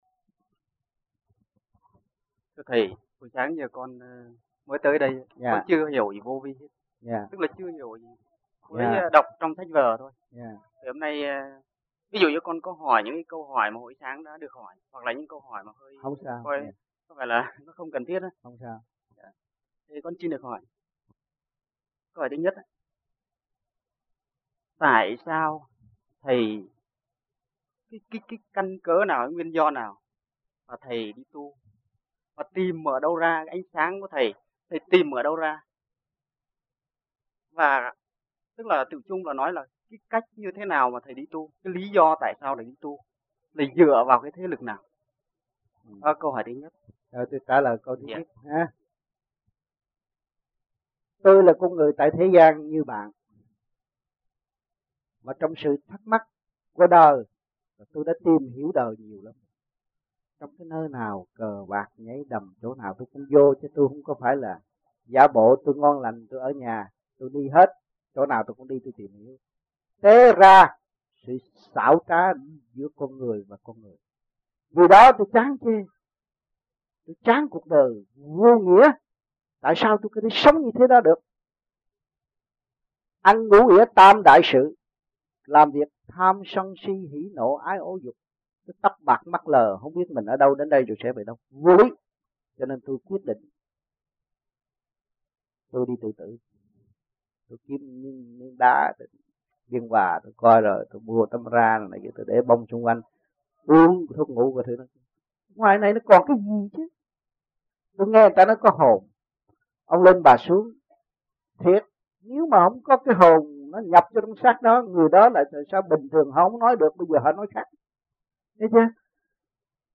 United States Trong dịp : Sinh hoạt thiền đường >> wide display >> Downloads